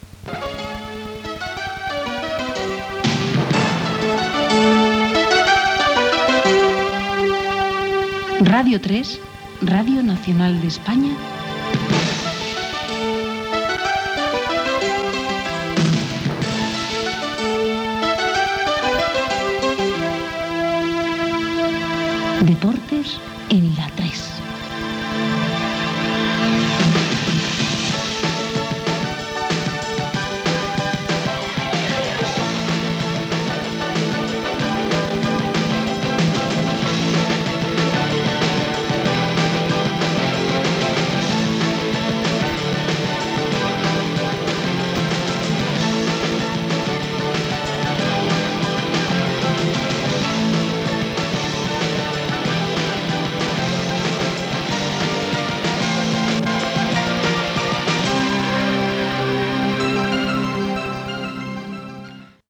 Careta del programa
Esportiu